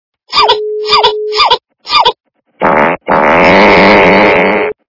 » Звуки » Смешные » Червяк - Непристойные звуки
При прослушивании Червяк - Непристойные звуки качество понижено и присутствуют гудки.
Звук Червяк - Непристойные звуки